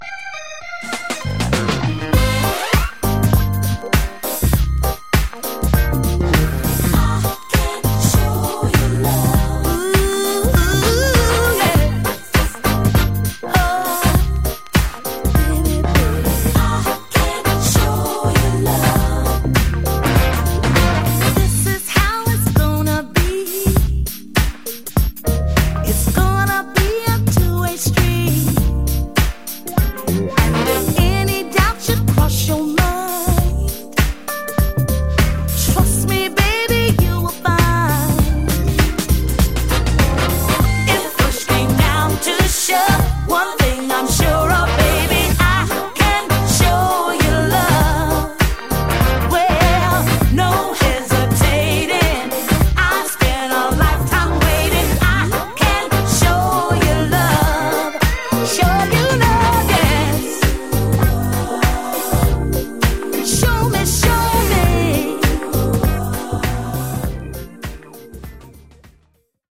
ソウルフルR&B グルーヴィ
【A 】キズなしの比較的良好な状態（たまに微小なチリノイズ有り）